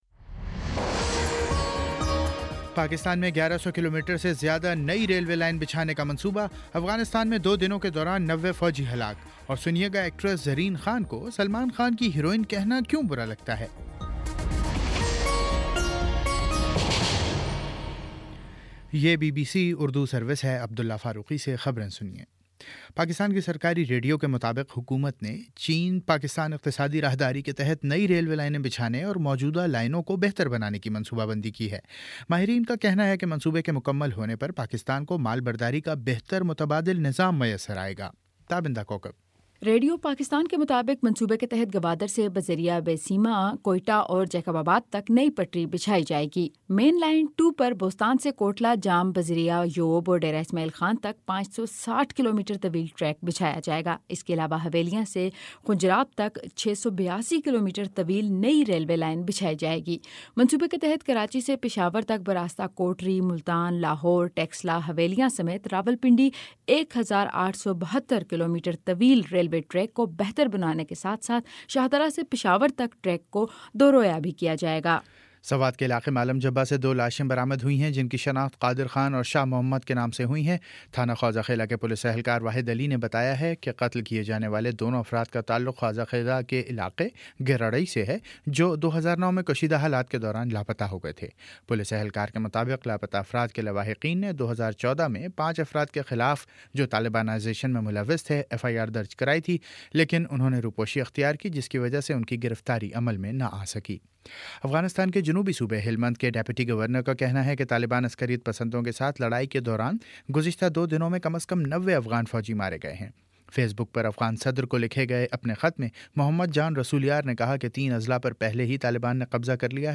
دسمبر 20: شام سات بجے کا نیوز بُلیٹن